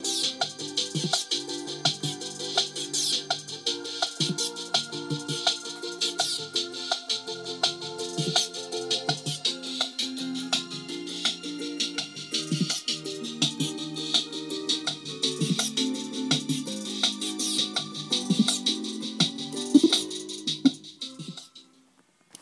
Sounds coming out of the schnauzer Studio....